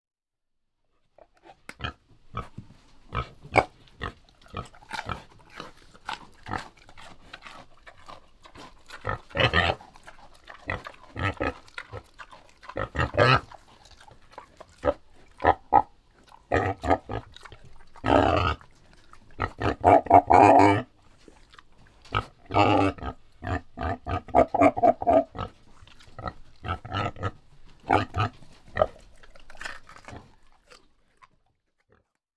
Звуки кабанов
Звуки кабана: голос и рев